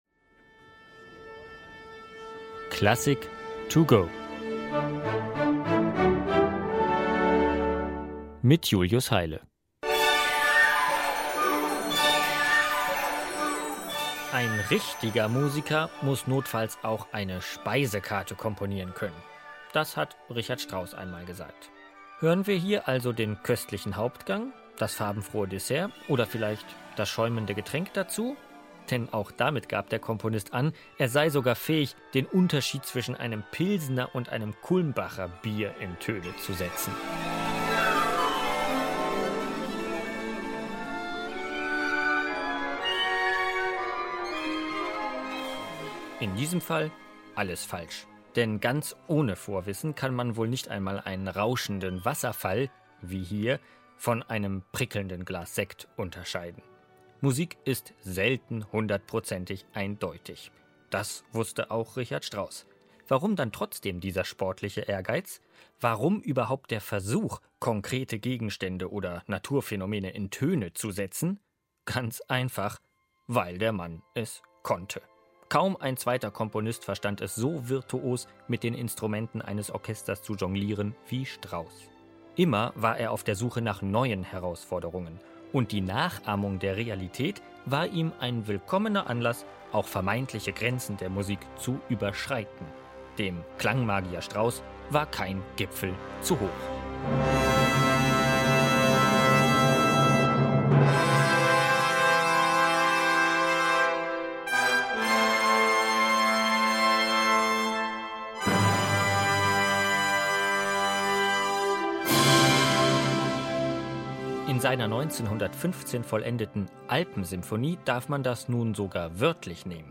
kurzen Werkeinführung für unterwegs.